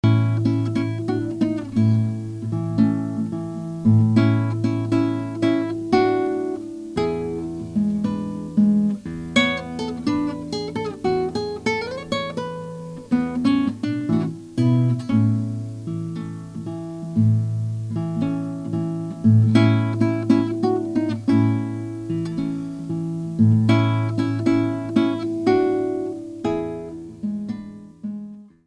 We were treated to the second movement of Vivaldi's guitar concerto, 'La Grima' by Francisco Tarrega, 'La Paloma' and a piece by a South American composer.